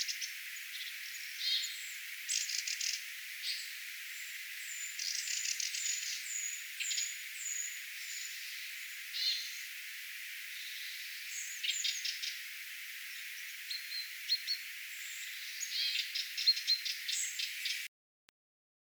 erikoista nuoren järripeipon ääntä?
olisiko_nuo_kolme_aanta_nuoren_jarripeipon_ehka.mp3